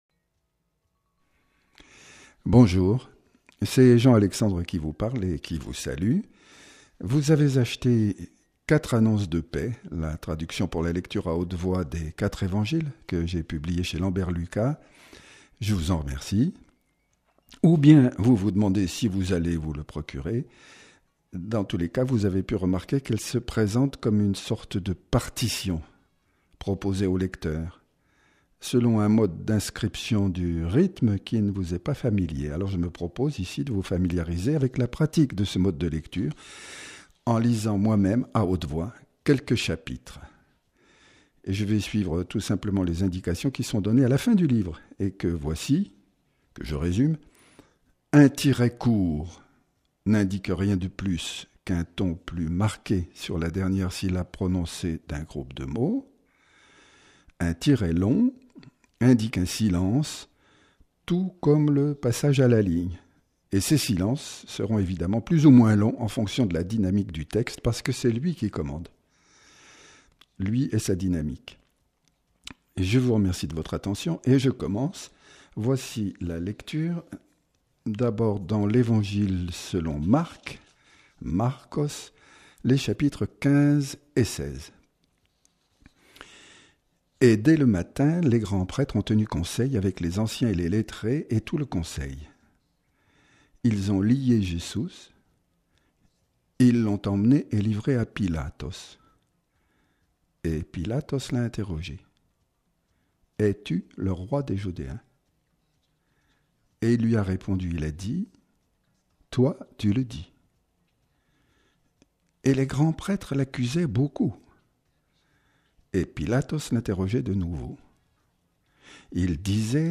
On peut écouter comment je lis ce genre de texte en allant sur